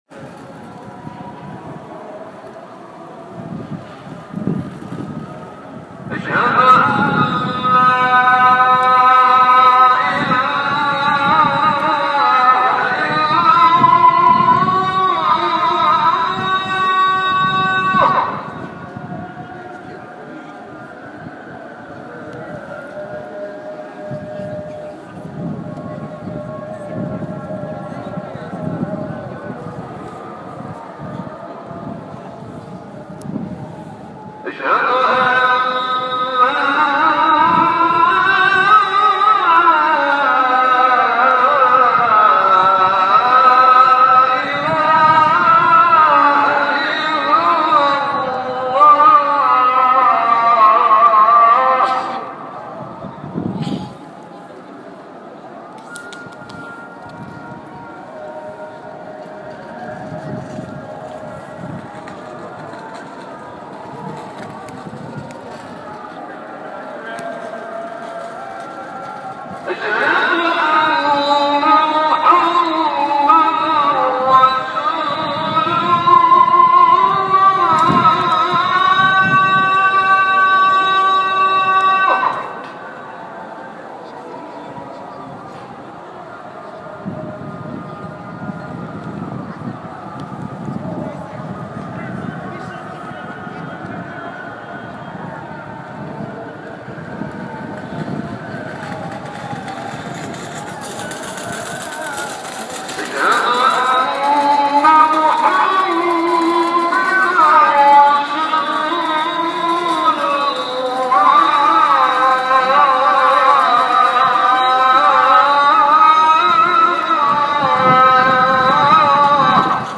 Je me pose sur un rebord, histoire de reprendre mes esprits et de noter deux trois impressions dans mon carnet et là, les minarets de la mosquée toute proche me hurlent dans les oreilles: frissons garantis!
L’extrait sonore est saisissant, on à vraiment l’impression d’être là avec toi dans la rue.
mosquee-istanbul.m4a